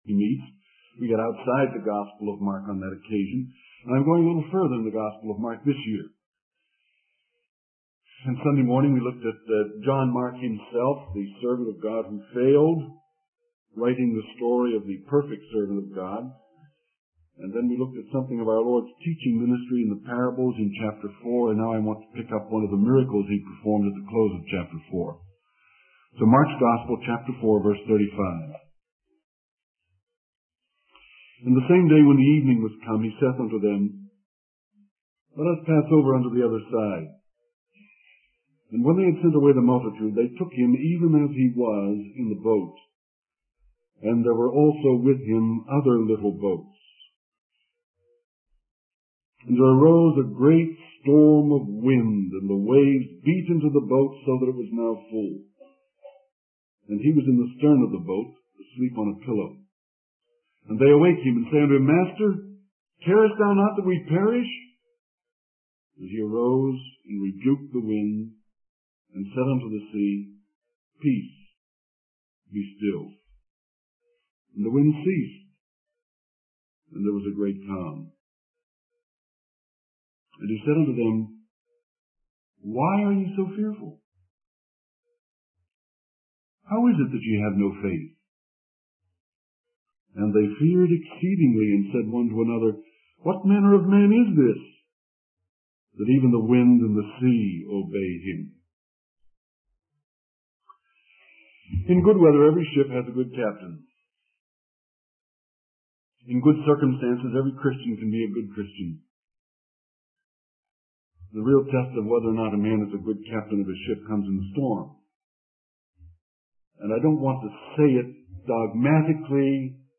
In this sermon, the speaker focuses on a miracle performed by Jesus in Mark chapter 4.